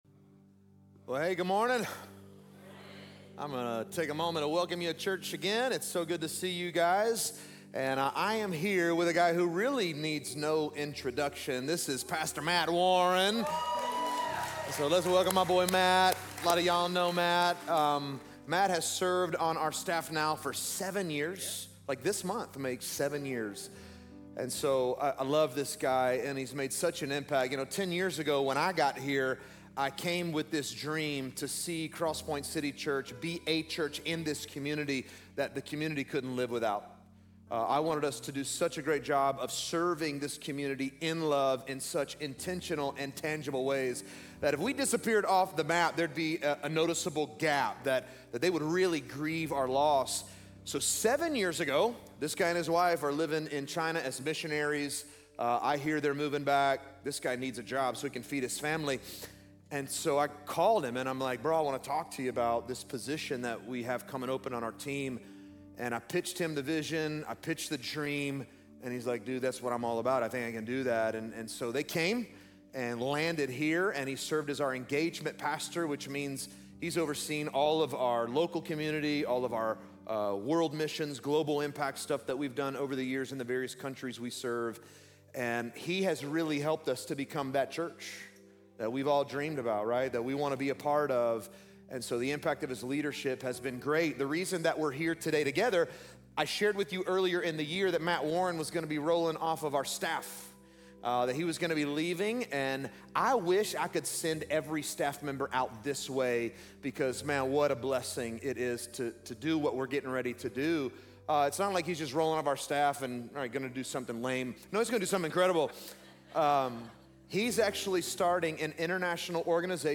This message is from our "The Gospel of John" series: "The Word Became Flesh."